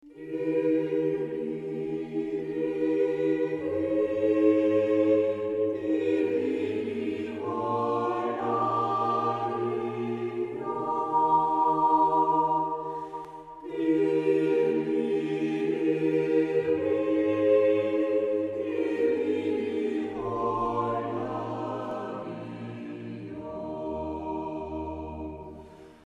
contribute to a contemplative atmosphere